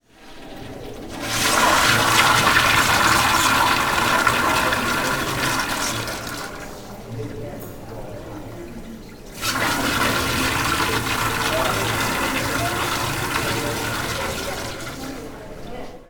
Sound file 1.4 A toilet flushing in the open dormitory bathroom